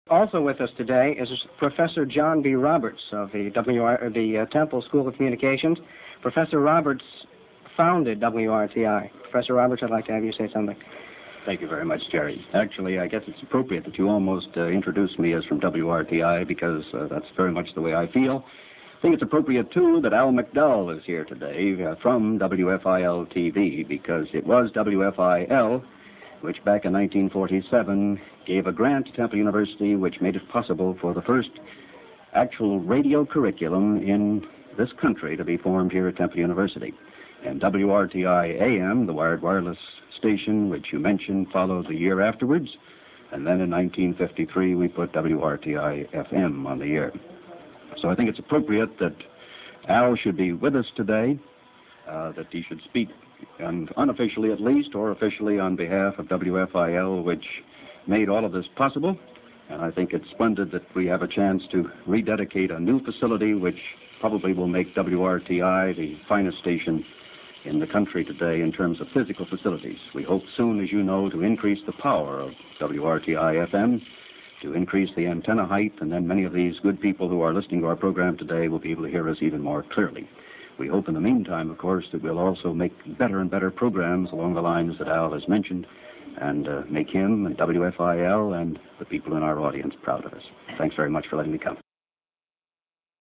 The First Broadcast from Annenberg Hall
Remarks
the noise in the background from 1:03 to 1:14 was a WFIL newsreel camera